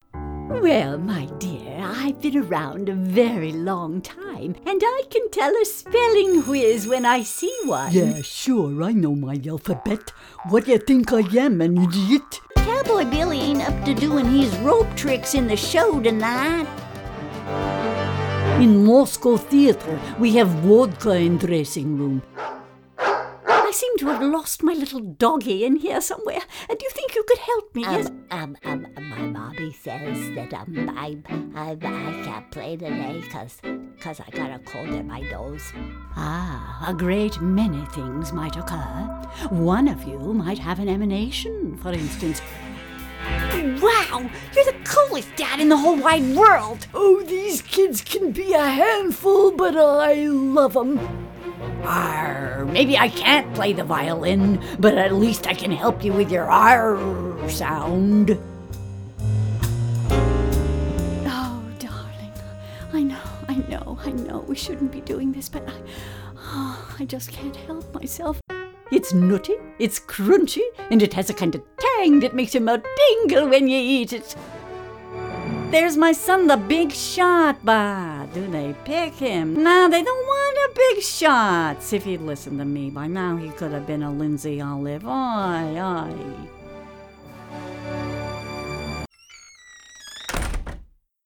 Character voices